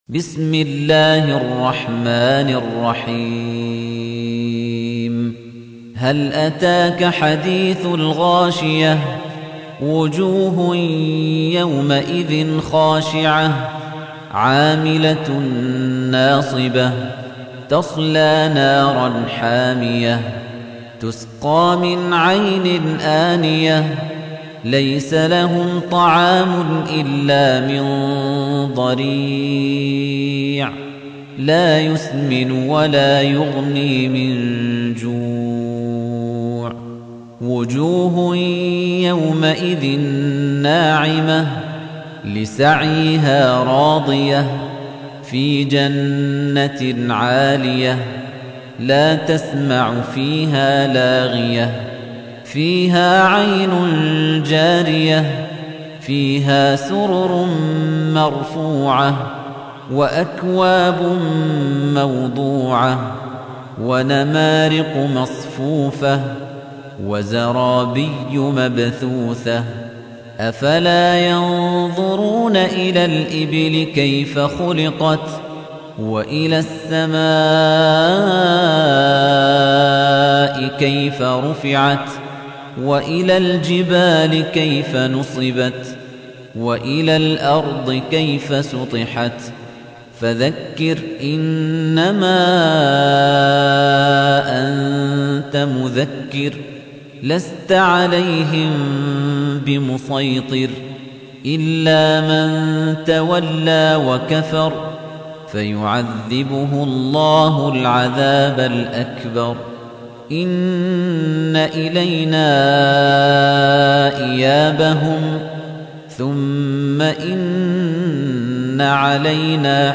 تحميل : 88. سورة الغاشية / القارئ خليفة الطنيجي / القرآن الكريم / موقع يا حسين